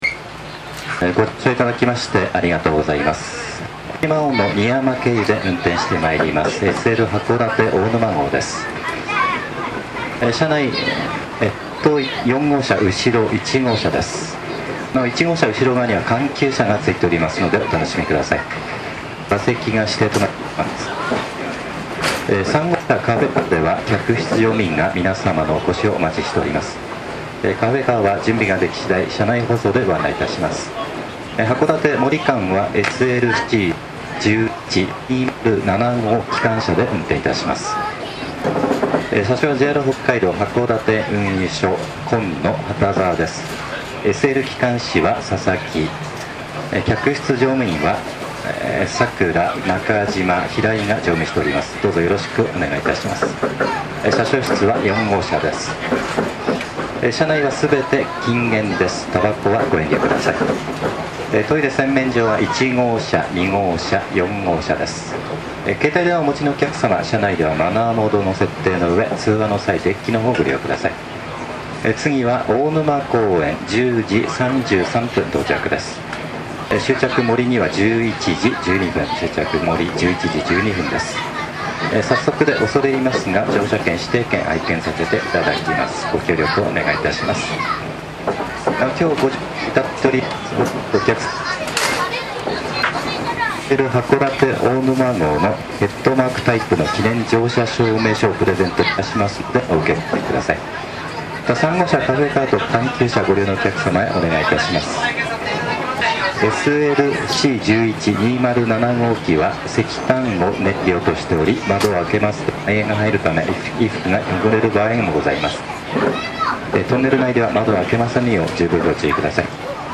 列車紹介アナウンス
またしても接触不良(泣)。